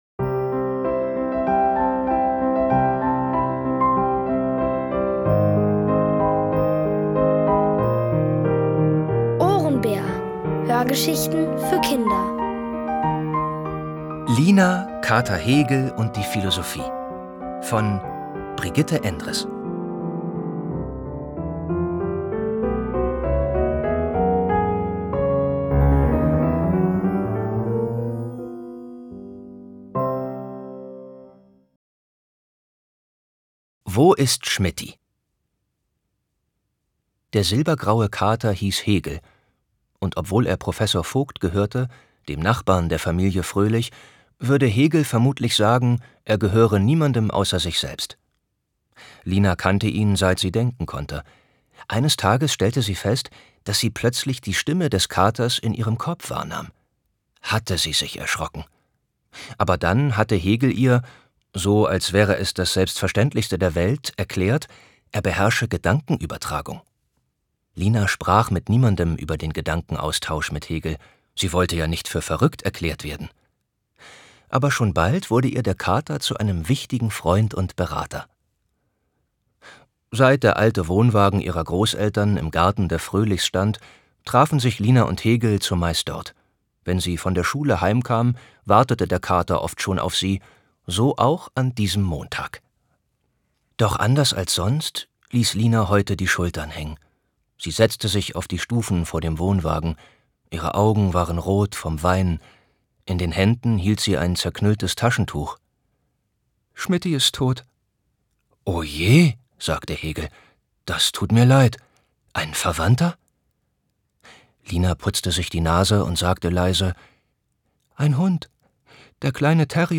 Von Autoren extra für die Reihe geschrieben und von bekannten Schauspielern gelesen.
OHRENBÄR-Hörgeschichte: Lina, Kater Hegel und die Philosophie